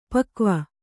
♪ pakva